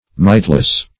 Mightless \Might"less\, a.